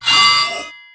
flag_drop.wav